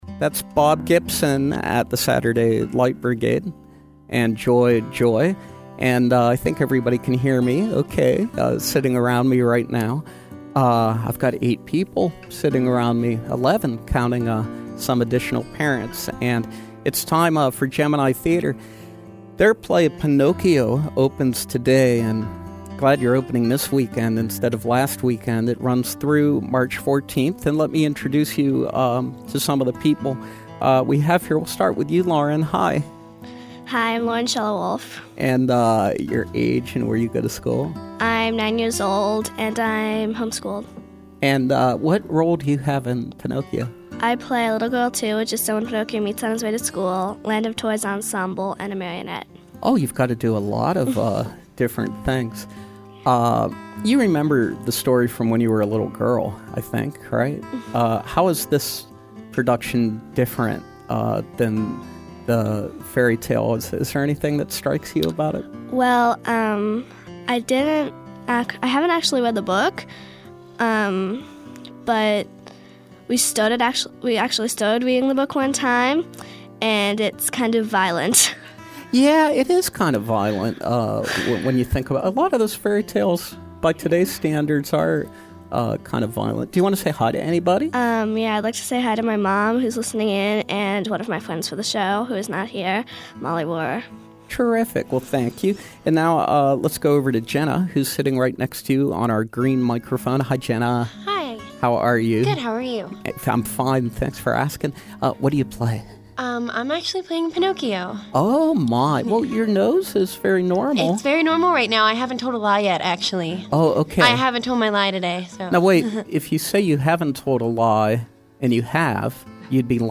The Gemini Theater produces original, interactive, children’s musicals which focus on artistic, cultural and educational themes. This week we welcome members of the cast of Pinocchio as they preview their production of this classic story live in our studios!